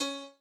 b_piano1_v100l128-7o6cp.ogg